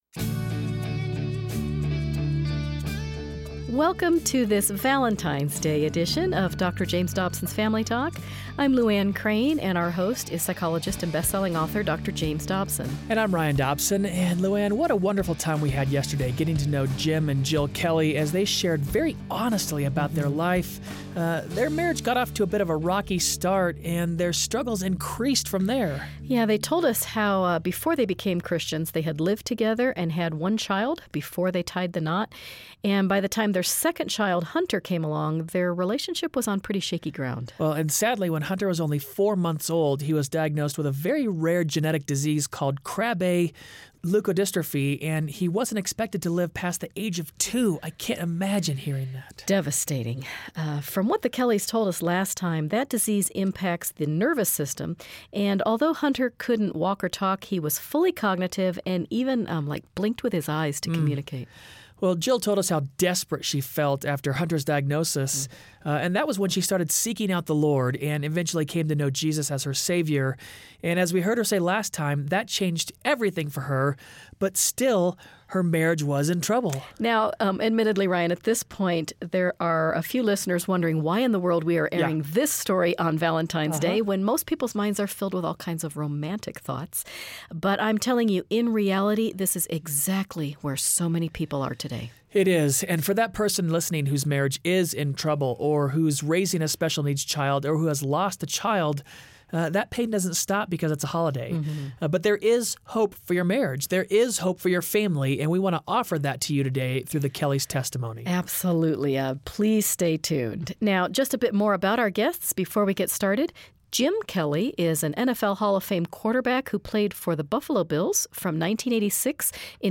Yet when tragedy struck their home, grief and infidelity almost split their family apart. They join Dr. Dobson to share how they are more in love today than ever before?